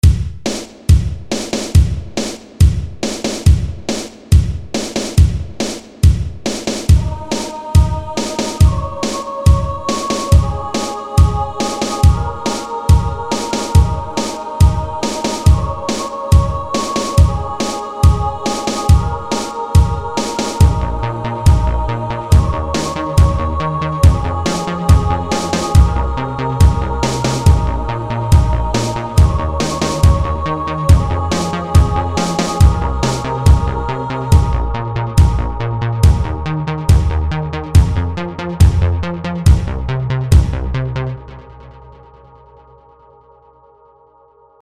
Yeah, I got these awesome new 80's drum samples.
Can you say Kraftwerk?